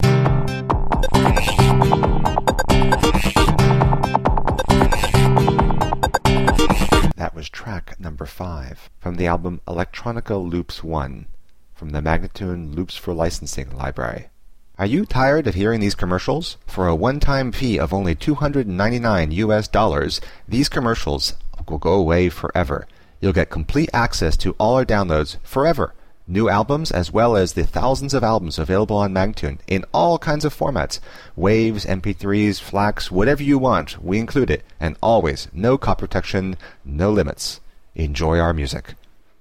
Instrumental samples in many genres.
135-C-ambient:teknology-1028